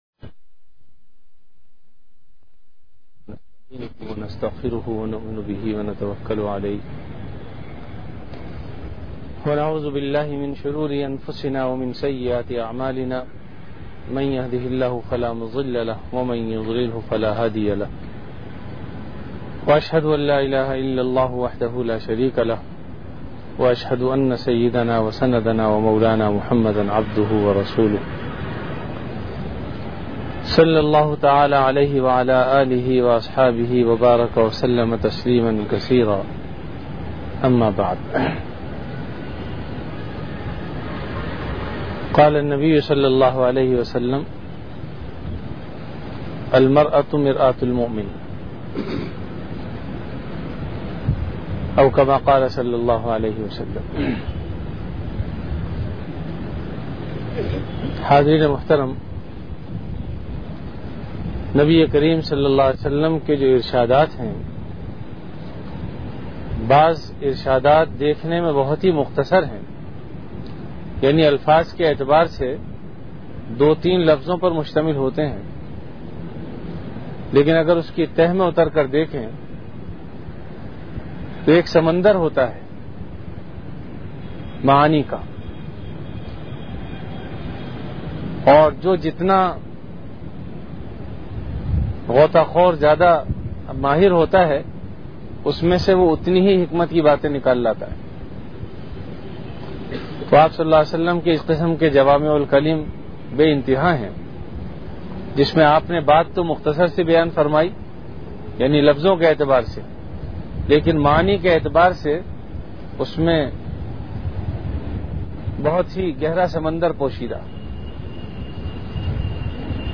Bayanat · Jamia Masjid Bait-ul-Mukkaram, Karachi
After Isha Prayer